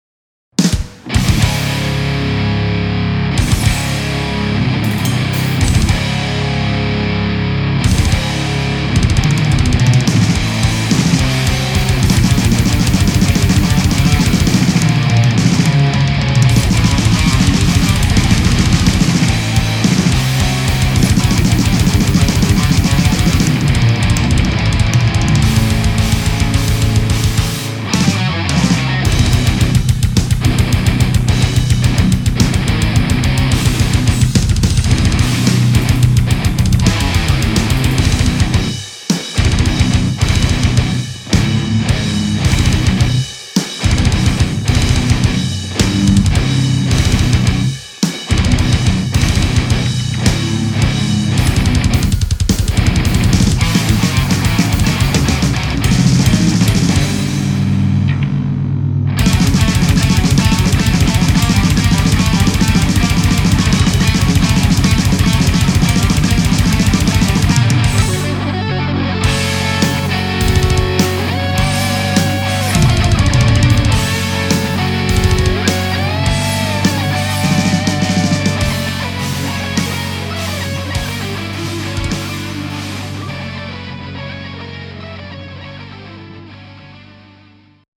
J'ai aussi foutu une compression parallèle sur la batterie et ca marche super .
je vais être pragmatique pour moi quand ya du blast et qu'on discerne bien les guitares c'est que l'enregistrement est bon !
Effectivement moi aussi je prefere le son sur forsaken, enfin surtout pour la batterie, j'ai un peu trop enlevé de reverb ici je trouve, j'en remettrais la prochaine fois, par contre pour le kick je compte garder celui-ci, je prefere quand ca sonne réaliste.
Sinon pour les guitares rythmiques j'ai enregistré 4 pistes, deux avec un gain normal, et deux autres avec un gain tres faible et une disto plus claquante.
Je préfère largement tes batteries maintenant, avec SD 2.0. Ça respire carrément plus que les anciennes avec ADrums je trouve.
Très gros son